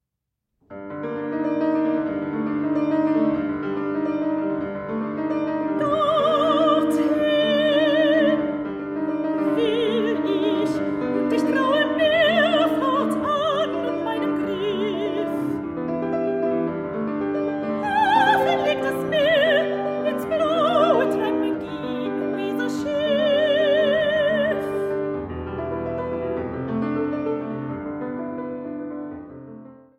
Sopran
Klavier